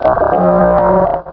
Cri de Wailmer dans Pokémon Rubis et Saphir.
Cri_0320_RS.ogg